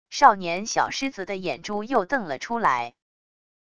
少年――小狮子的眼珠又瞪了出来wav音频